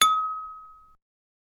highbell
bell chime chiming ding ring ringing sound effect free sound royalty free Sound Effects